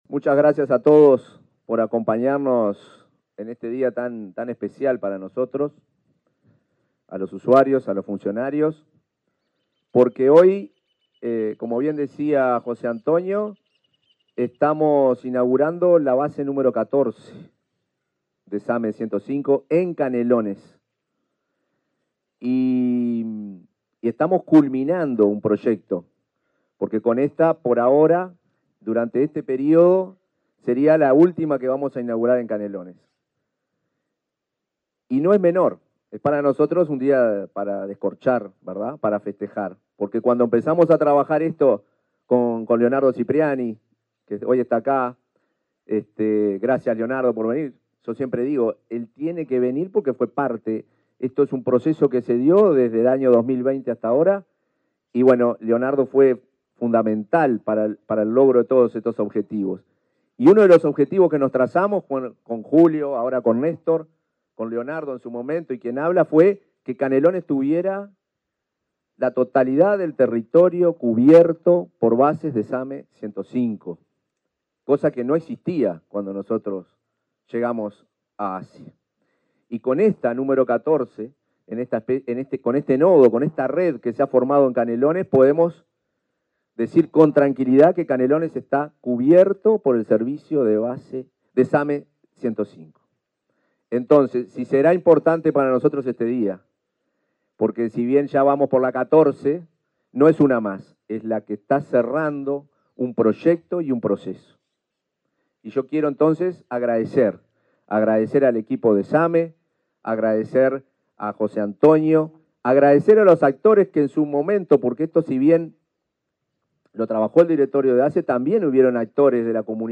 Palabras del presidente de ASSE, Marcelo Sosa
Palabras del presidente de ASSE, Marcelo Sosa 11/11/2024 Compartir Facebook X Copiar enlace WhatsApp LinkedIn Este lunes 11, el presidente de la Administración de los Servicios de Salud del Estado (ASSE), Marcelo Sosa, encabezó el acto de inauguración de una base del Sistema de Atención Médica de Emergencia (SAME) 105 en el balneario San Luis, departamento de Canelones.